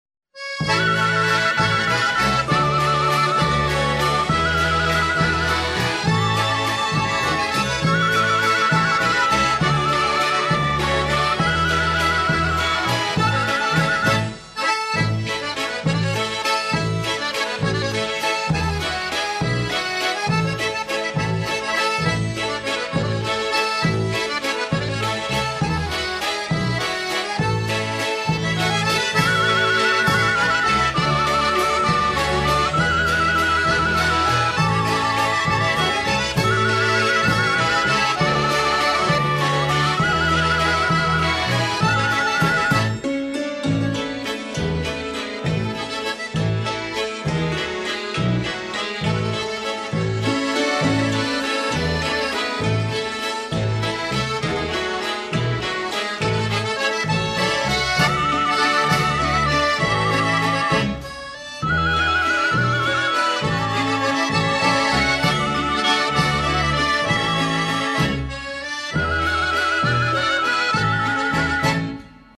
grande soirée festive de Musiques et Danses traditionnelles de Biélorussie.
culture traditionnelle dans un univers festif et flamboyant.
L'orchestre utilise plus de cent instruments originaux et chaque danse bénéficie de costumes particuliers.
Festive_Waltz.mp3